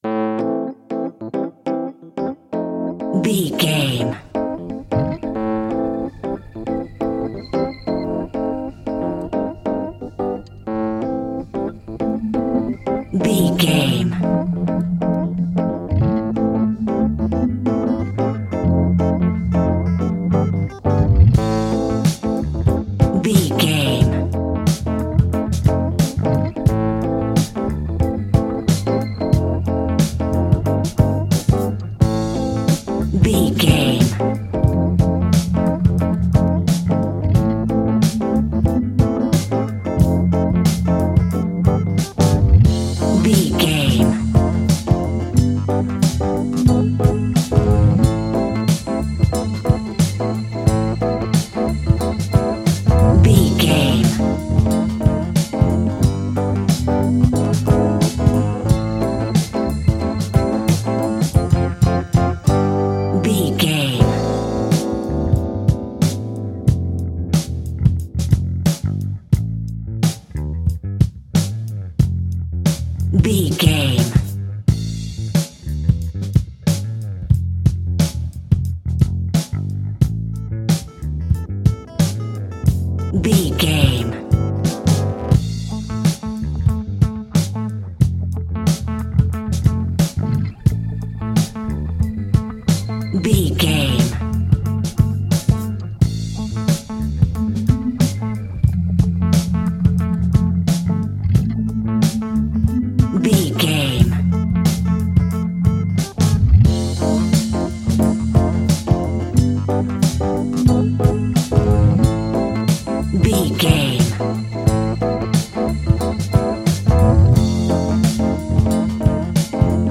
Aeolian/Minor
funky
uplifting
bass guitar
electric guitar
organ
drums
saxophone